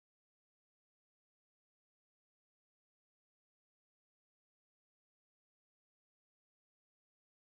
Airplane ✈ Passing In Front Sound Effects Free Download